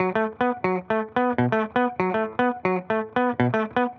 Index of /musicradar/dusty-funk-samples/Guitar/120bpm
DF_BPupTele_120-B.wav